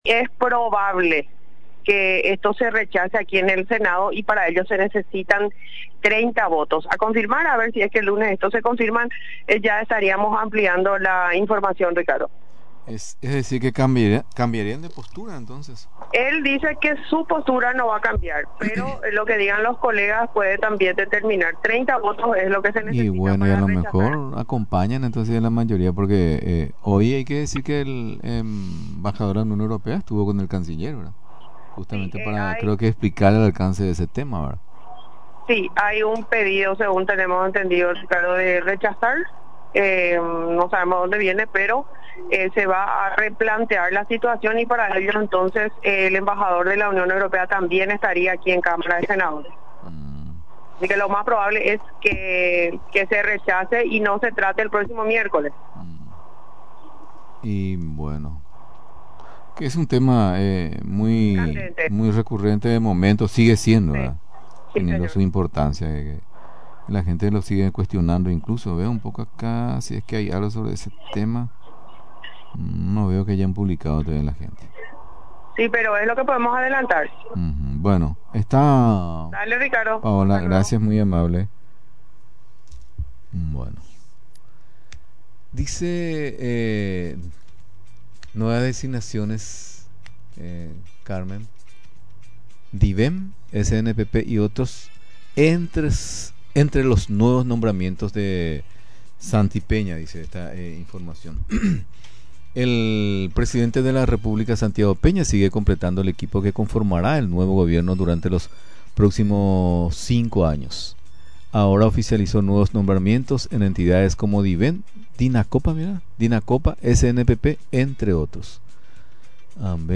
Con el objetivo de ofrecer visitas turísticas en las zonas que ofrecen la tranquilidad del lugar, proyectan la reactivación del tren, destacó este viernes el intendente de la ciudad de Paraguarí, Marcelo Simbrón.
Resaltó en la 920 Am, en el programa Paraguay Puede, que el tramo utilizado para la reactivación del tren, será Paraguarí, Ypacaraí y Pirayú.